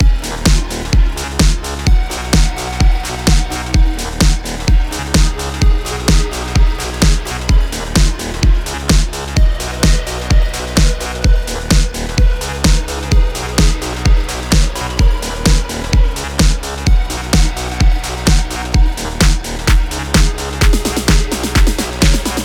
Music - Song Key
Gb Major
Nice Cut
Robotic Bass
Bit Simple
Trash Chop